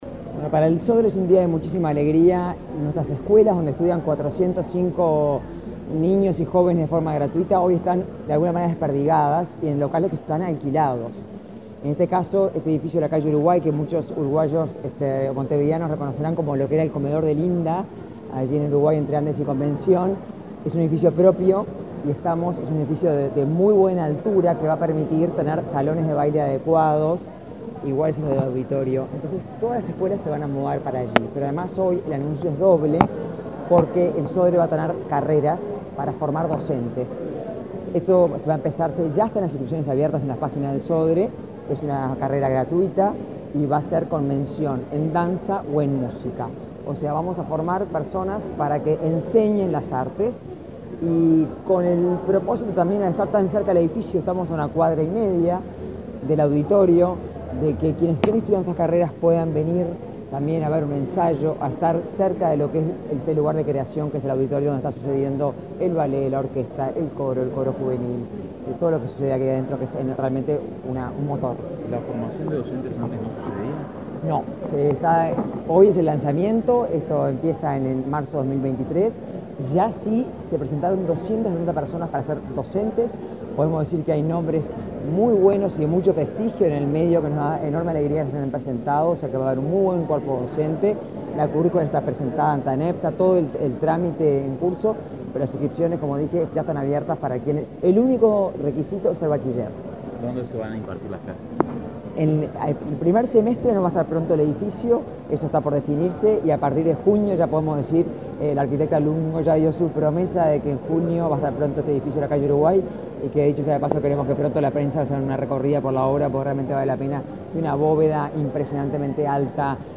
Declaraciones de la presidenta del Sodre a la prensa
Luego, Dubra dialogó con la prensa.